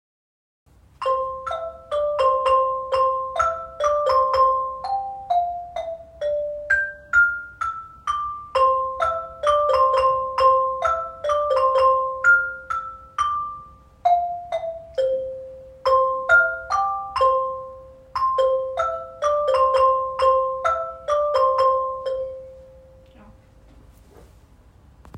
Inspiriert von einem Rondo in C-Dur von Ludwig van Beethoven, entstanden in Teamwork sieben kurze Rondos auf Xylofon, Metallofon und am Klavier – alles in Eigenregie der Klasse 7e am Maristengymnasium Fürstenzell.